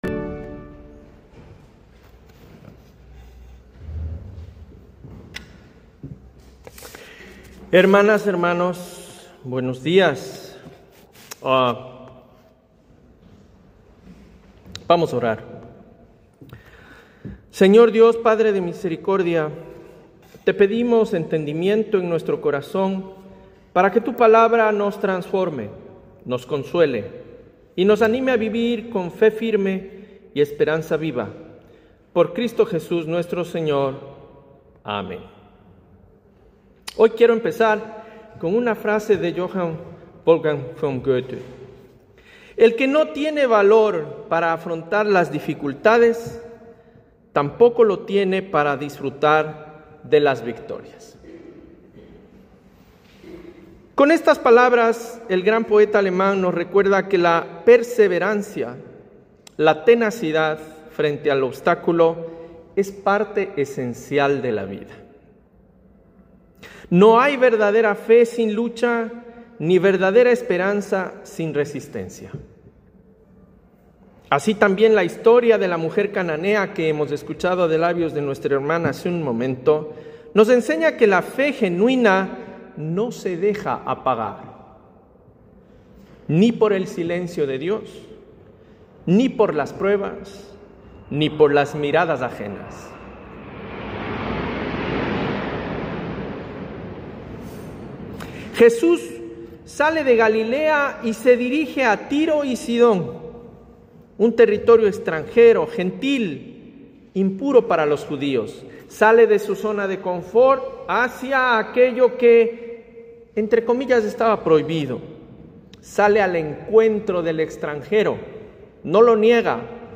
Sermón: “La fe que no se rinde” Friedenskirche – Iglesia Alemana de La Paz Domingo 12 de octubre de 2025 Basado en Mateo 15:21-28